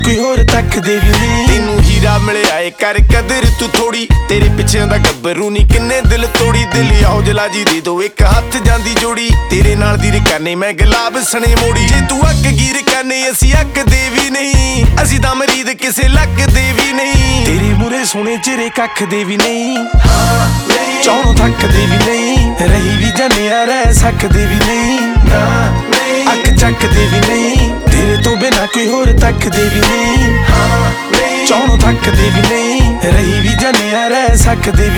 Pop Hip-Hop Rap
Жанр: Хип-Хоп / Рэп / Поп музыка